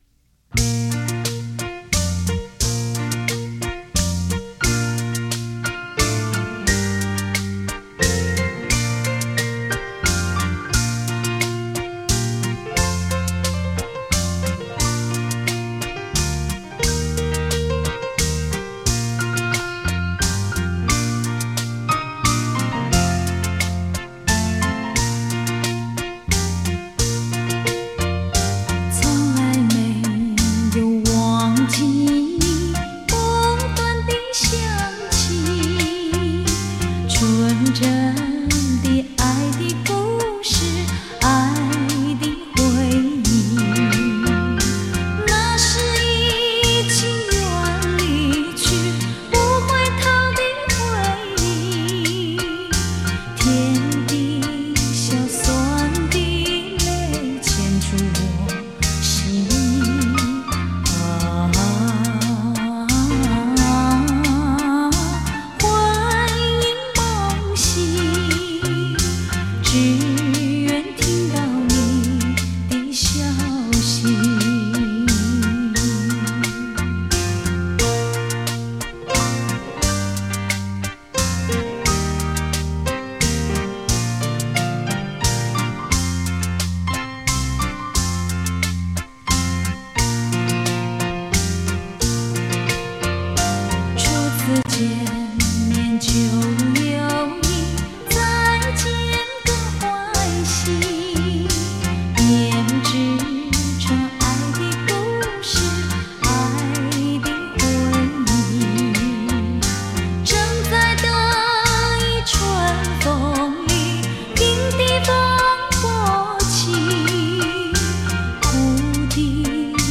双钢琴现场演奏，熟悉好歌精选